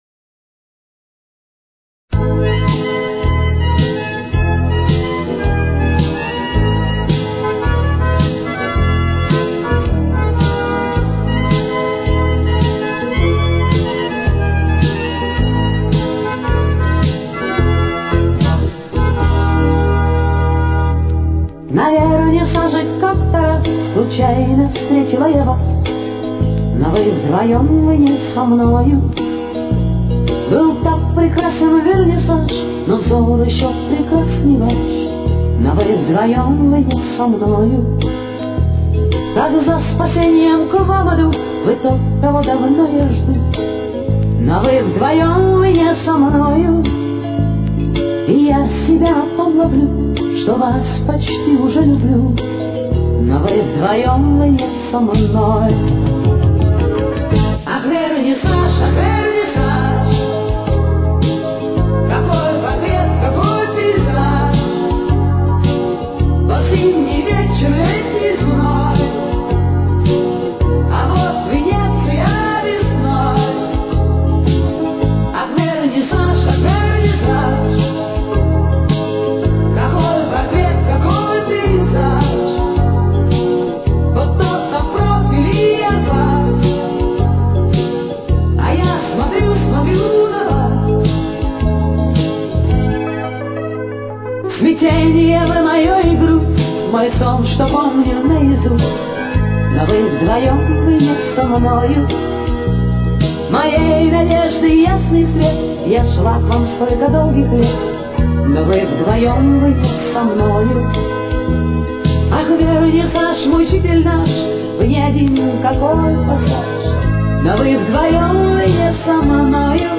с очень низким качеством (16 – 32 кБит/с)
Темп: 111.